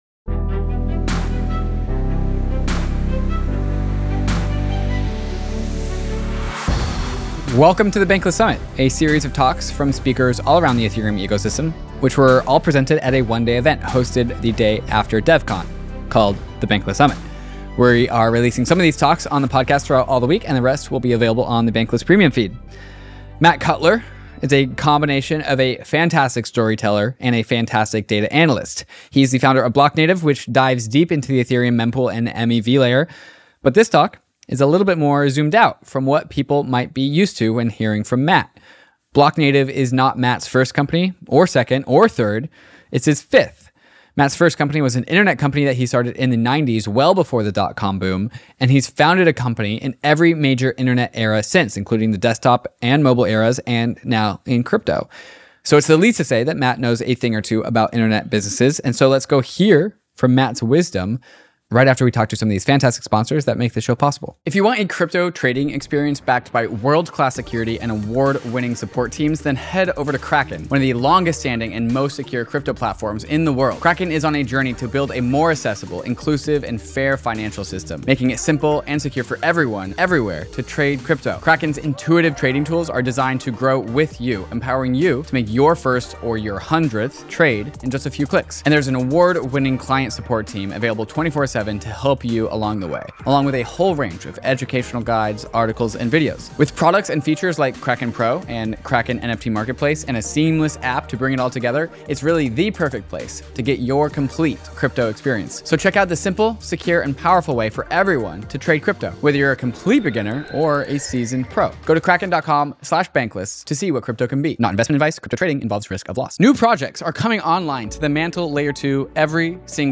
In today’s Bankless Summit talk